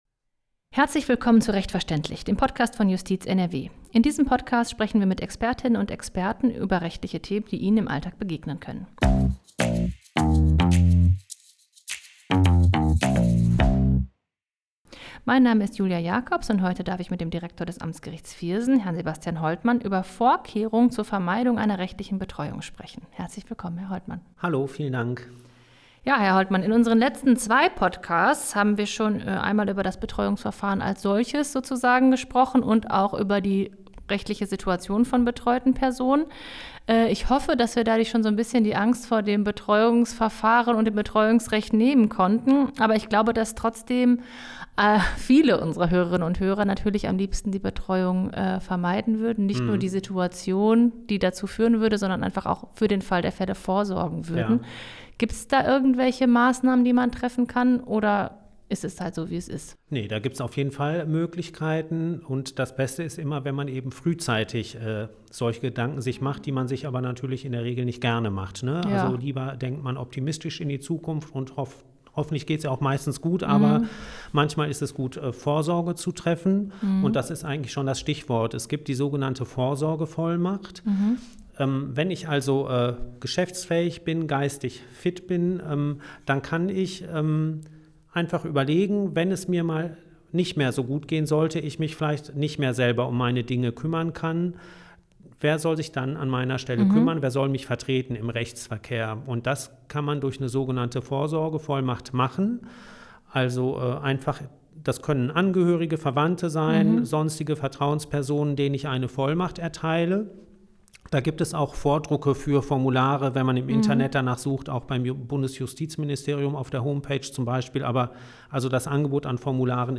Was versteht man unter einer Vorsorgevollmacht, was regelt sie und wer muss sie unterschreiben? In welchem Verhältnis stehen Betreuung und Vollmacht zueinander? Und was sind eigentlich Betreuungs- und Patientenverfügungen? Wertvolle Tipps und Einblicke liefert Sebastian Holtmann, Direktor des Amtsgerichts Viersen.
Interview_001_01.mp3